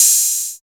80 OP HAT.wav